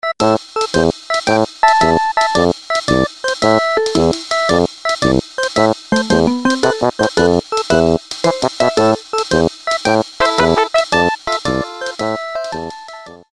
Nokia полифония. Мультики